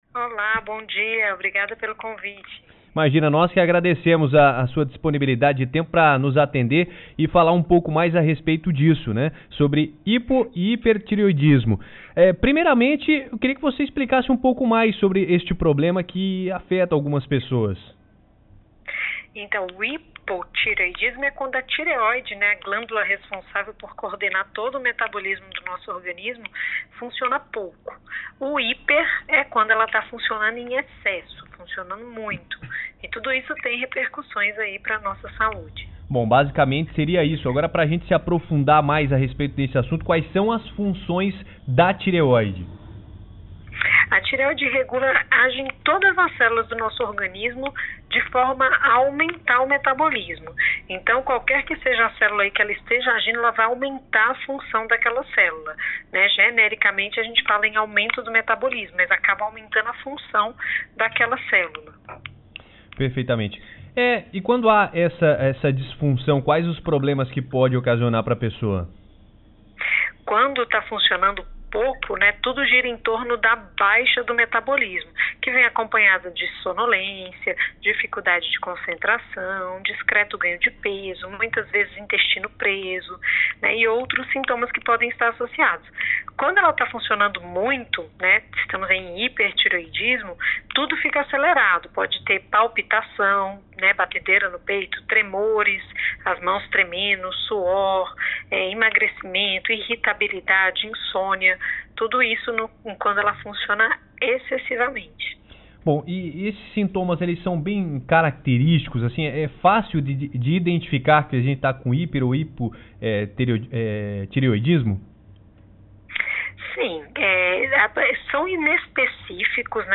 Confira a entrevista realizada no programa Ponto de Encontro: